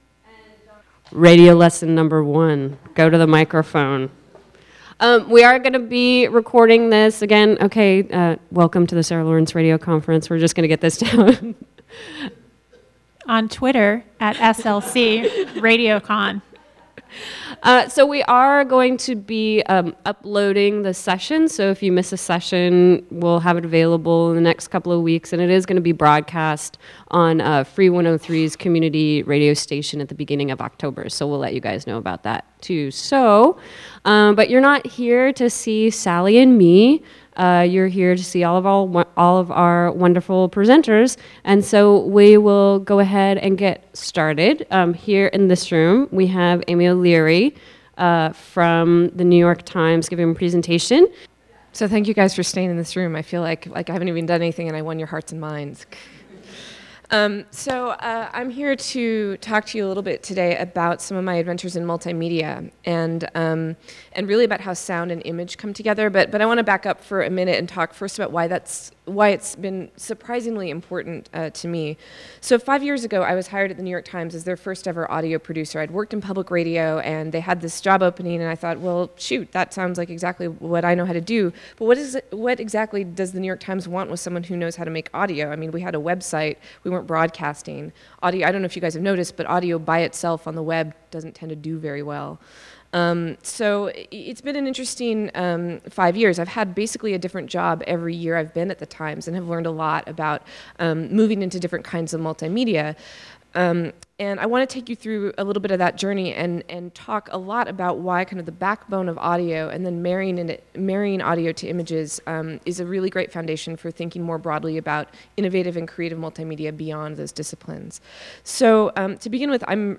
Saturday Night Special: Free Radio Conference at Sarah Lawrence College : Oct 06, 2012: 7pm - Oct 07, 2012: 2am
A broadcast of selected sessions from the Free Rad...
From "Free Radio Conference" at Sarah Lawrence College.